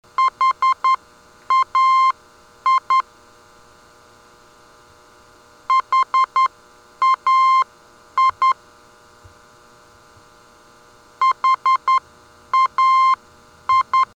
HAI - Three Rivers heard on 407 kHz: (222 kb)